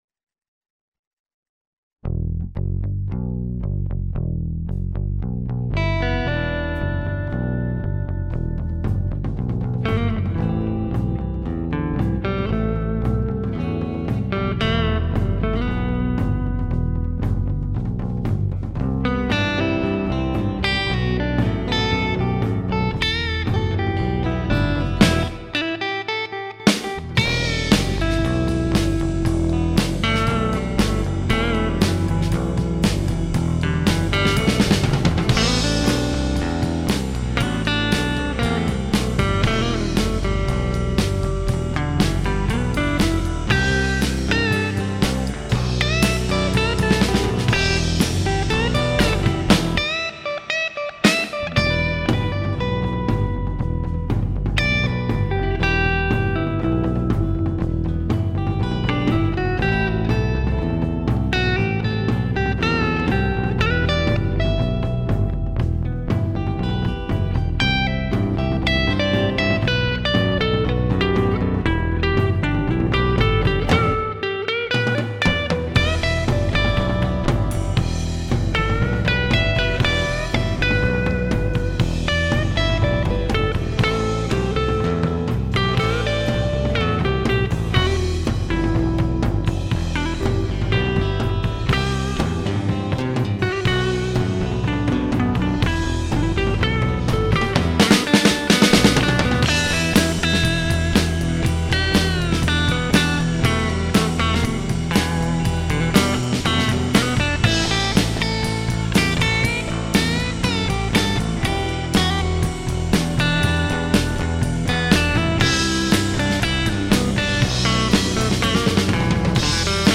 This is just a spontainious jam I did when doing a headphone soundcheck (and tape was rolling). It's a American Strat, neck pickup into an amp I made. Attachments Smurfs Up.mp3 Smurfs Up.mp3 4.4 MB · Views: 59